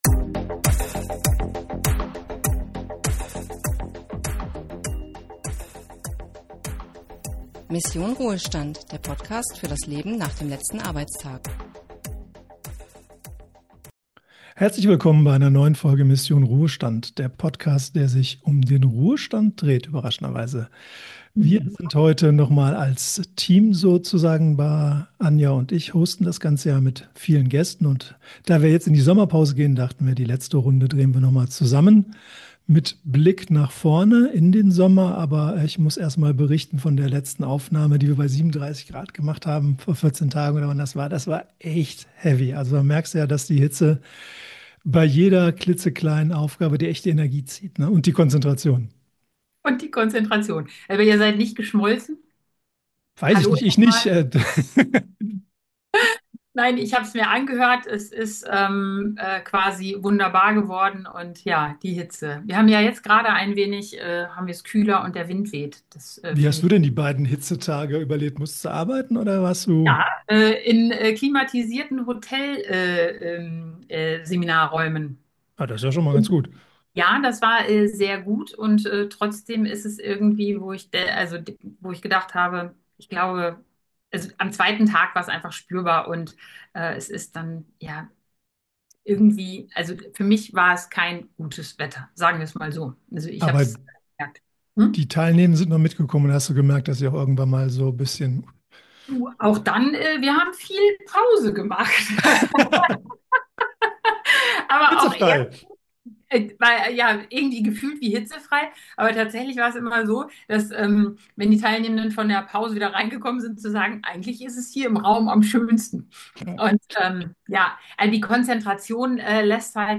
Ein entspannter Gedankenaustausch mit Blick auf das...